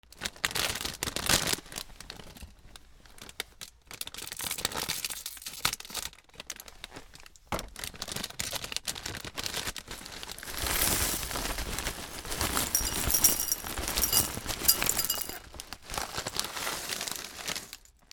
シリアルを容器に入れる